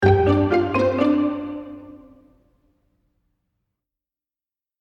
10秒BGM （103件）